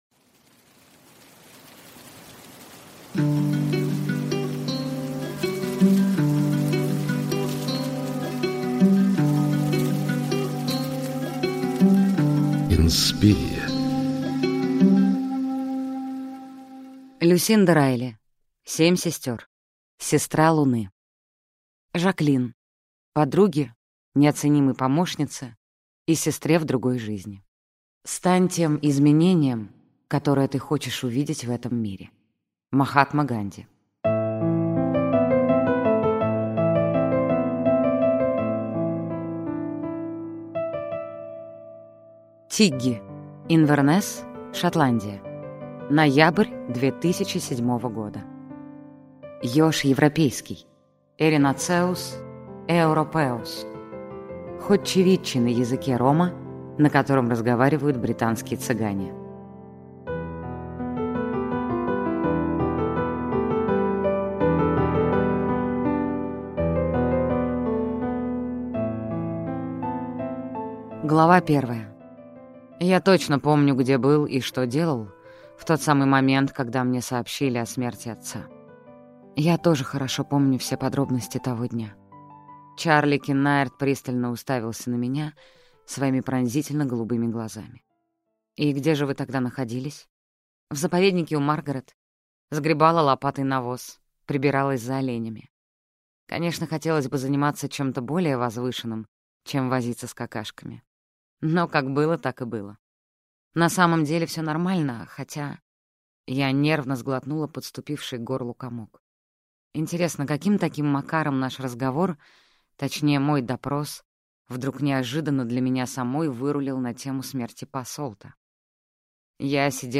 Аудиокнига Семь сестер. Сестра луны | Библиотека аудиокниг